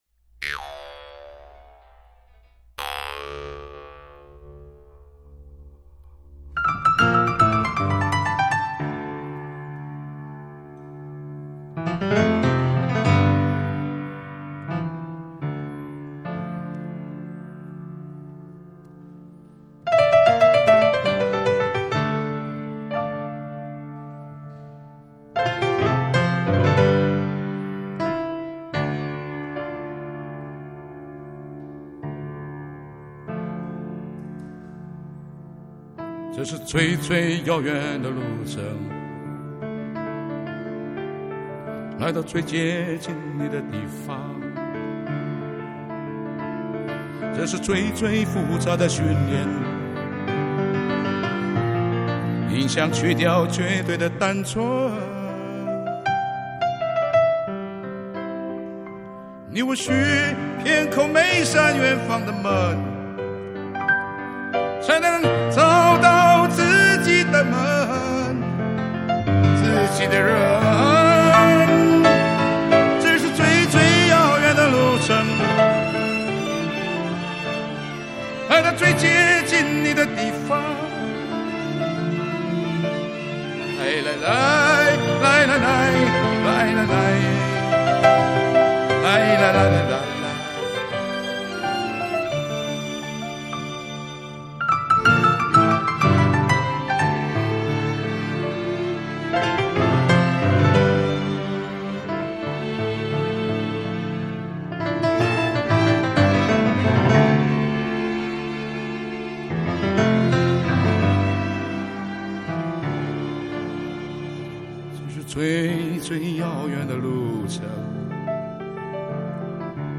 時值盛暑，聒噪的蟬聲也跟著鋼琴一起入了歌。